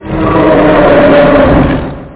monster.mp3